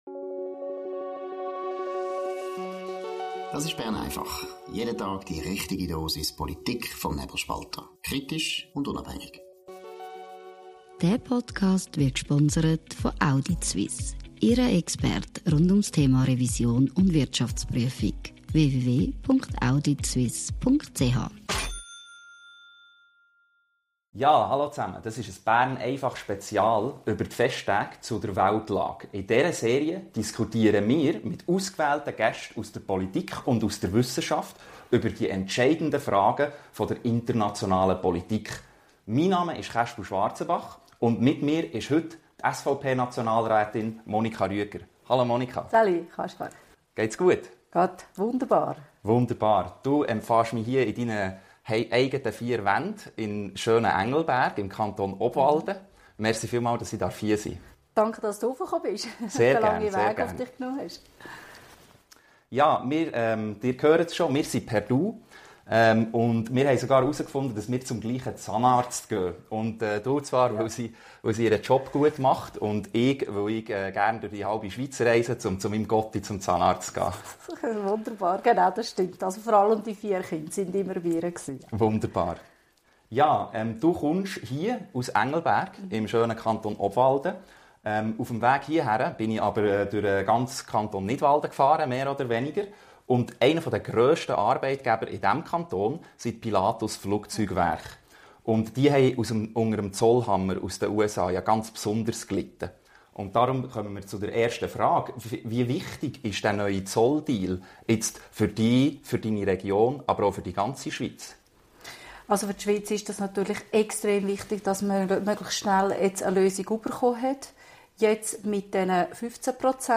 Bern einfach Spezial zur Weltlage: Die Redaktion des Nebelspalters spricht mit ausgewählten Gästen aus Politik, Journalismus und Wissenschaft über die entscheidenden Fragen der internationalen Politik.